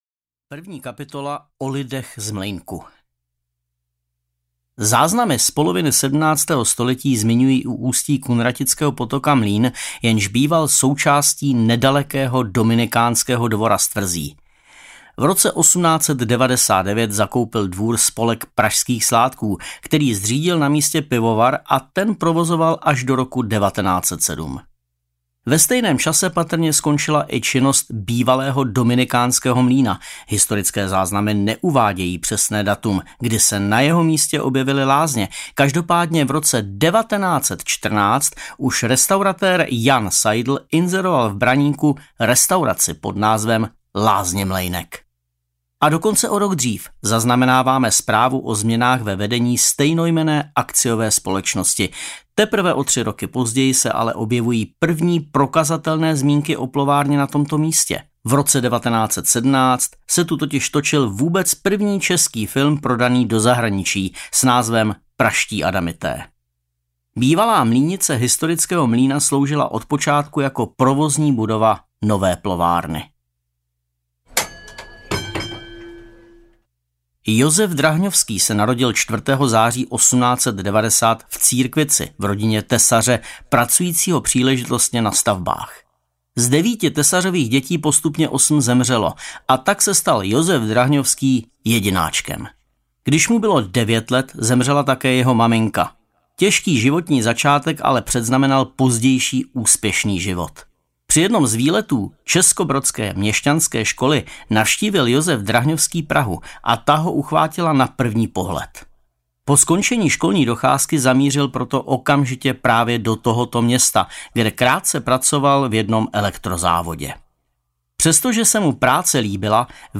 Praha v proměnách času audiokniha
Ukázka z knihy
praha-v-promenach-casu-audiokniha